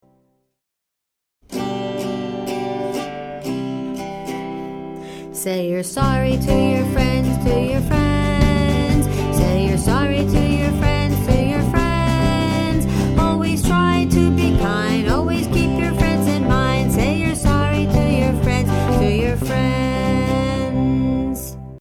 we are preschool teachers, not professional singers )